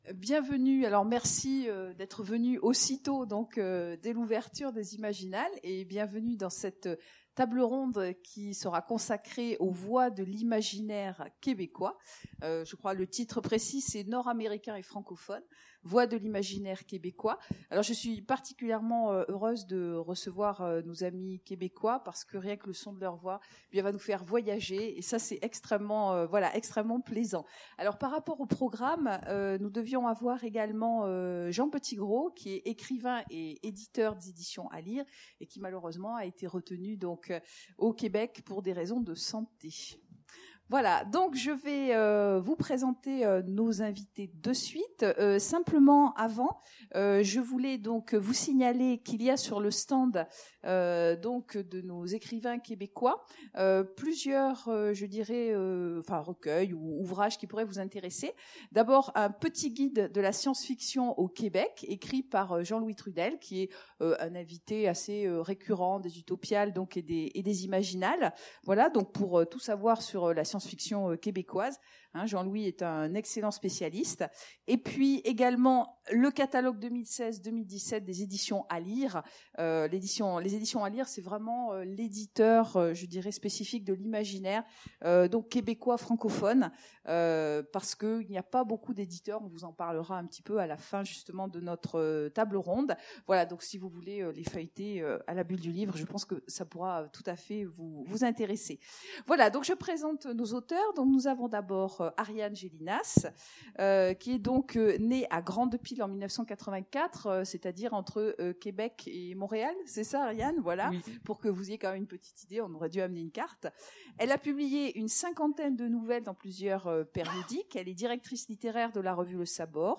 Imaginales 2017 : Conférence Nord-Américains et francophones : Voix de l'imaginaire québécois
Conférence